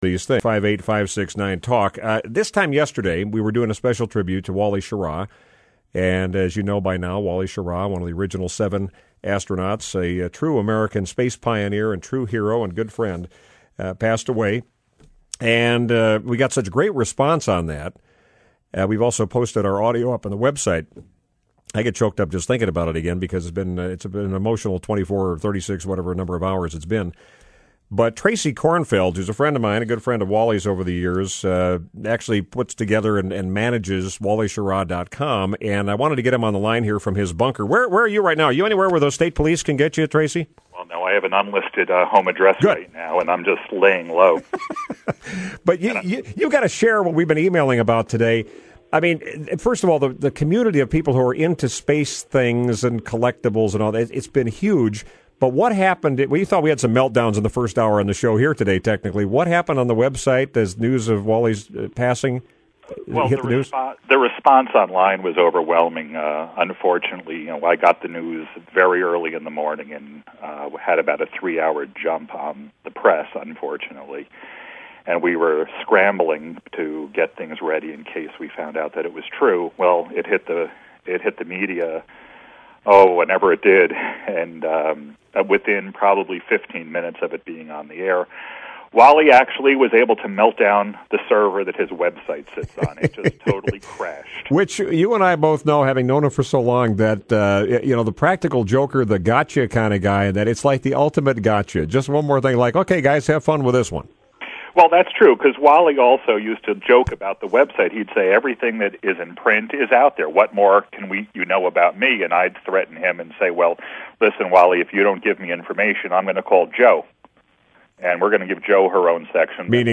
Radio Commentary